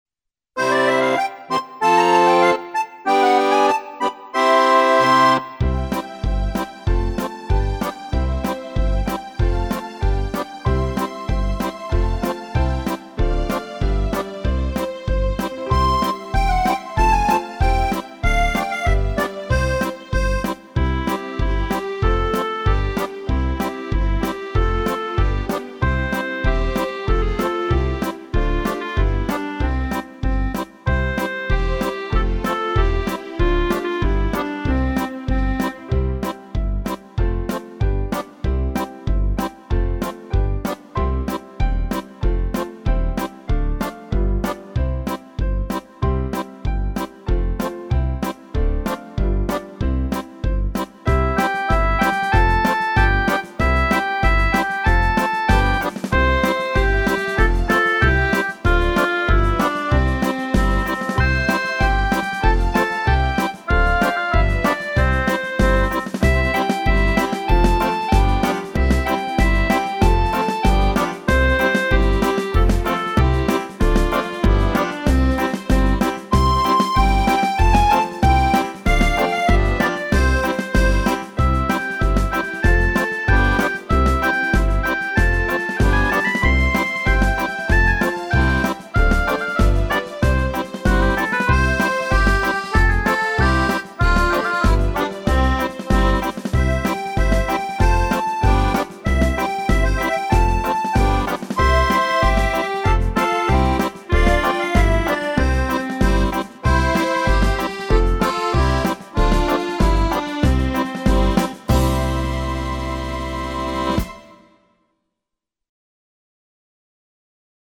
Песня-пляска для детей 4 лет
фонограмму (минус)